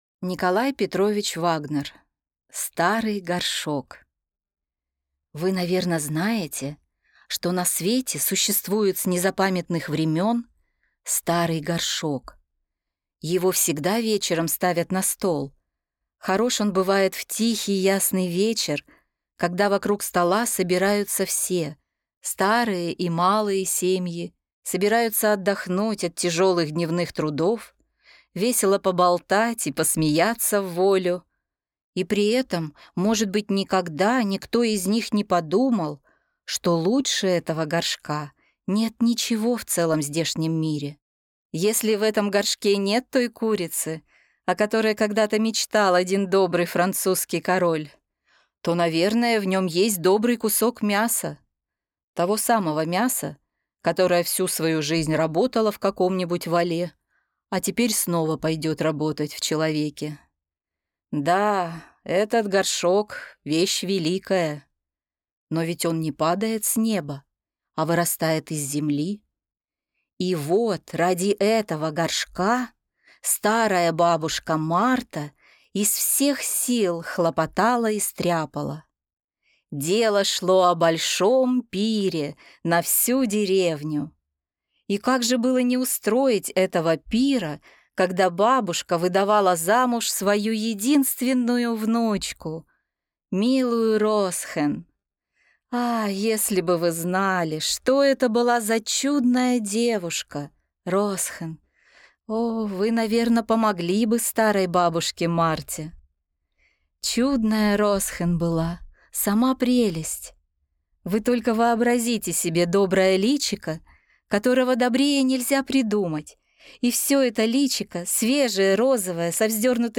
Аудиокнига Старый горшок | Библиотека аудиокниг